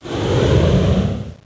Warp.mp3